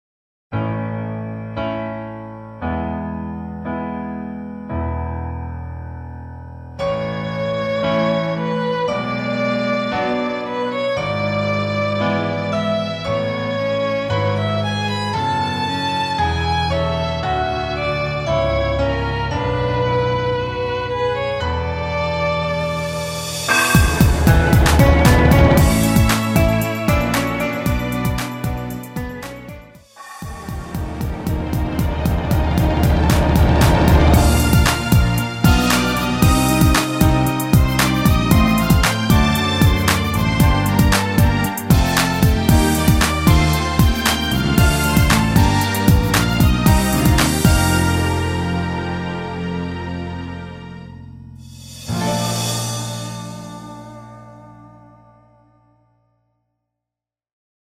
전주 없이 시작 하는 곡이라 전주 2마디 만들어 놓았습니다.